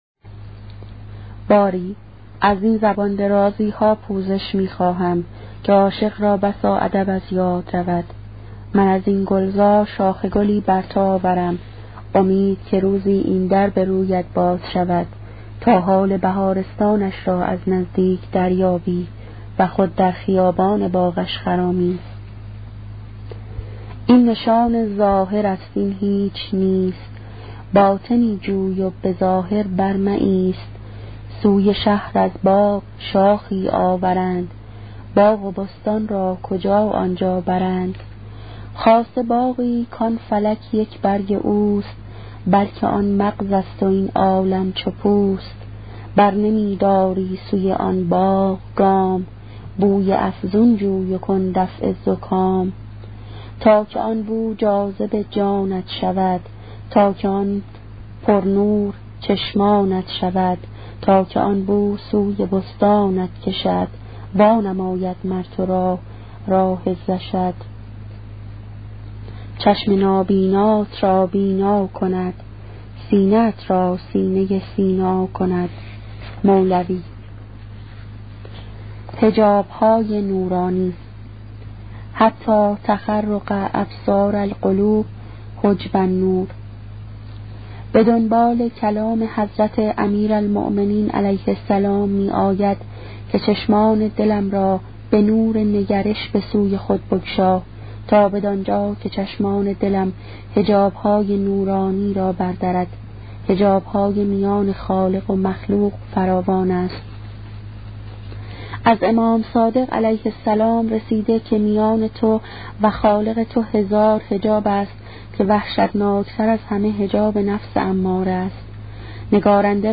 کتاب صوتی عبادت عاشقانه , قسمت پنجم